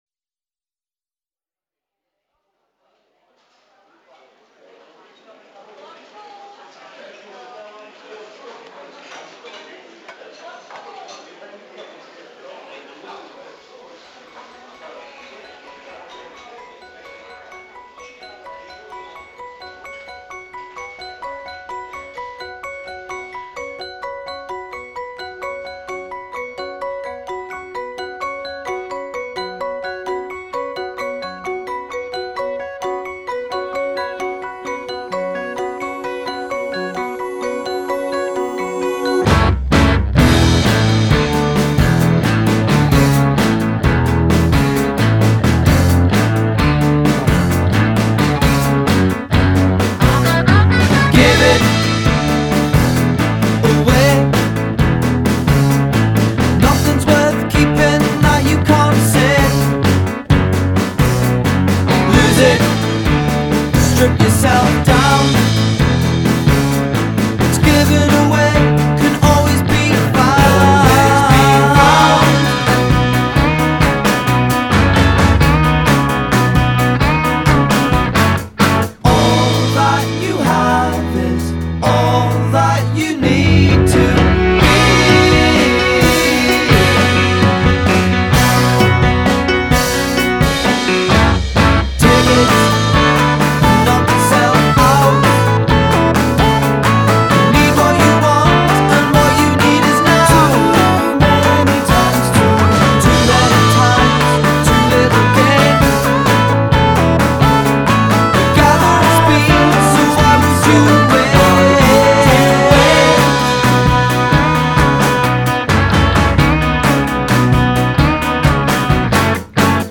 Yeah – a bit of Yes proggy-ness without the “non-pop” bits.
God, it’s so Yes-like it’s ridiculous.